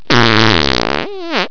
fart1.wav